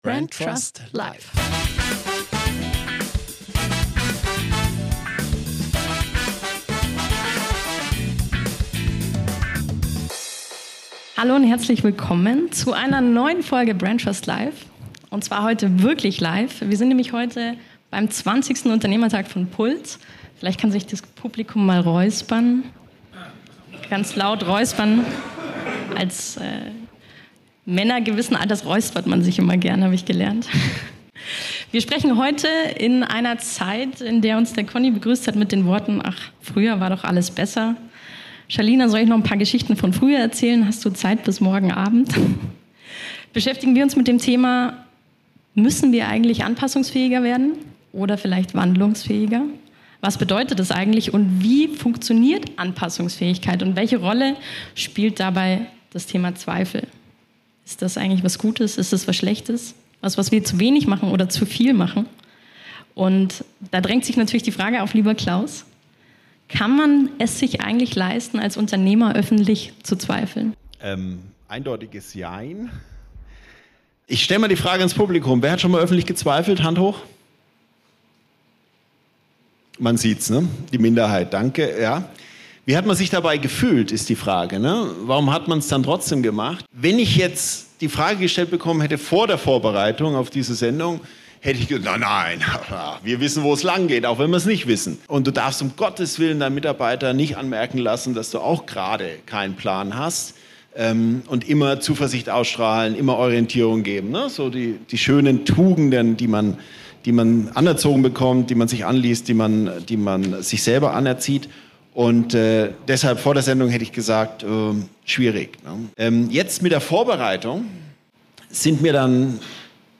Zweifel & Wandlungsfähigkeit: Live auf dem puls Unternehmertag (#25) ~ BrandTrust Life